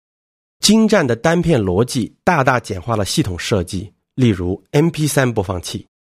sprecherdemos
chinesisch m_01